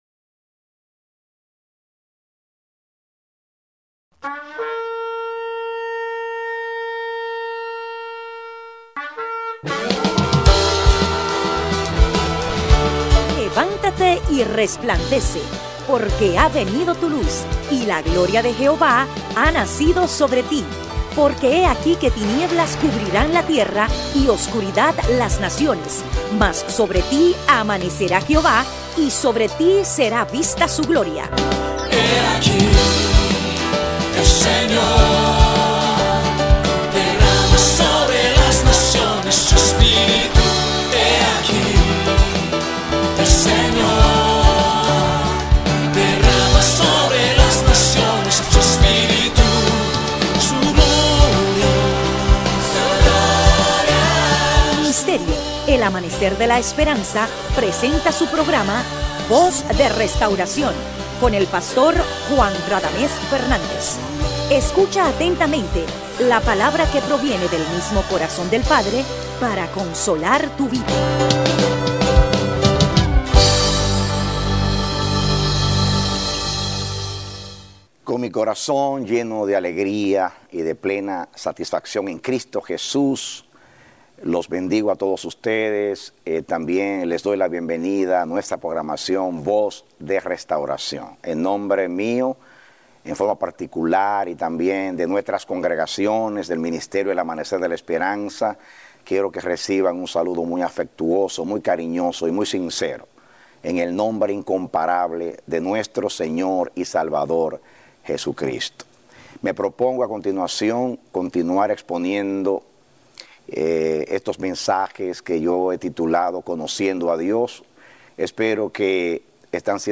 Mensajes TVs – El Amanecer de la Esperanza Ministry